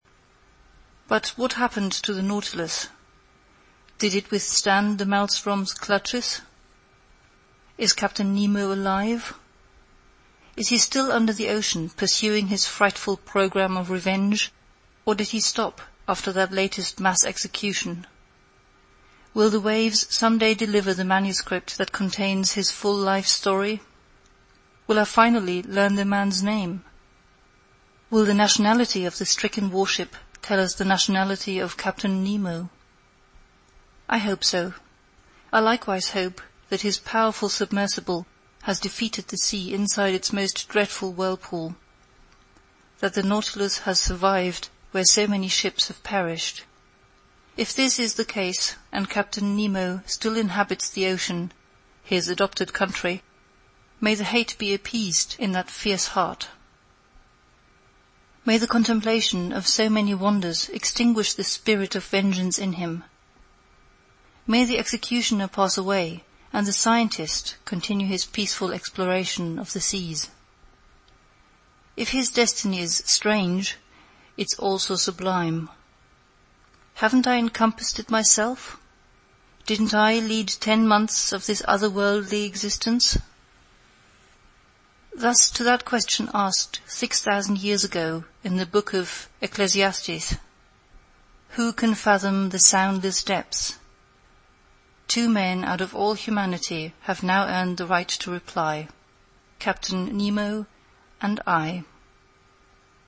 在线英语听力室英语听书《海底两万里》第567期 第36章 结尾(2)的听力文件下载,《海底两万里》中英双语有声读物附MP3下载